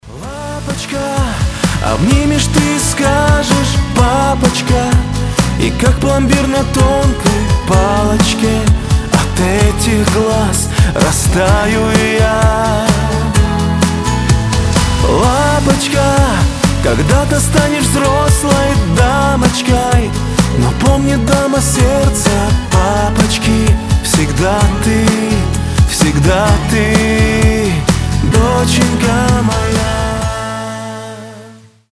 • Качество: 320, Stereo
милые
добрые